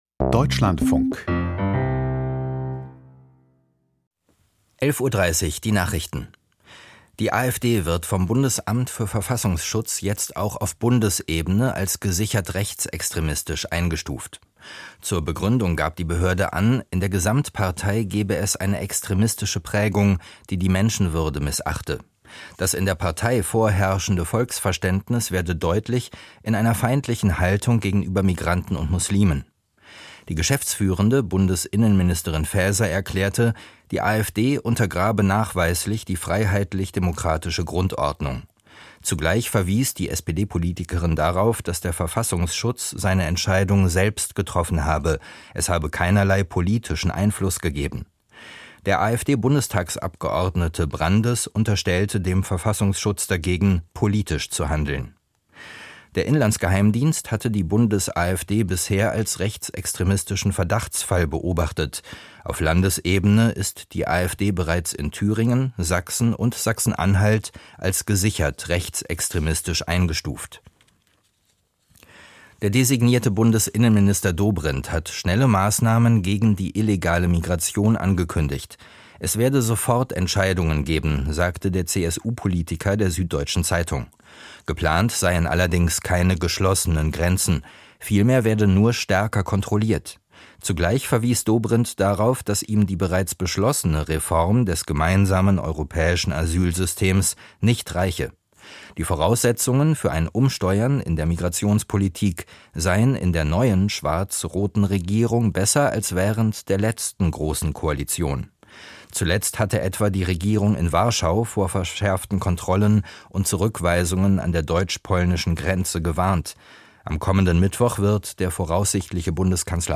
Die Deutschlandfunk-Nachrichten vom 02.05.2025, 11:30 Uhr